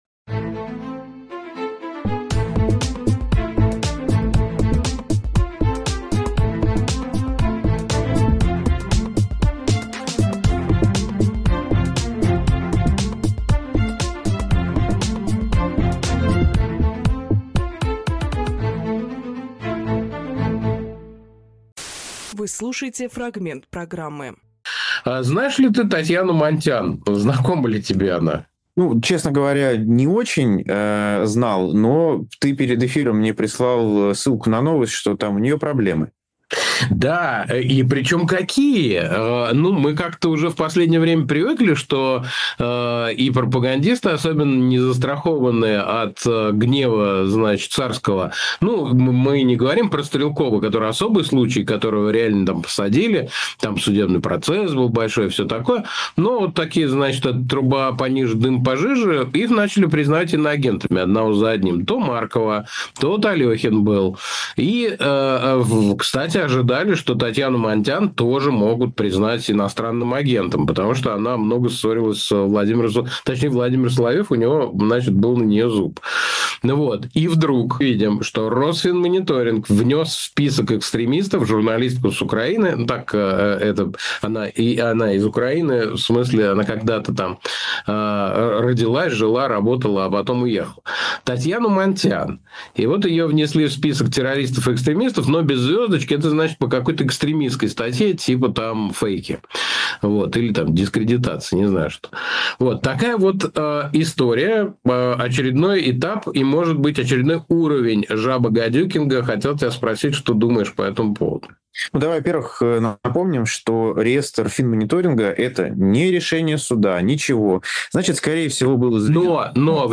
Фрагмент эфира от 27.10.25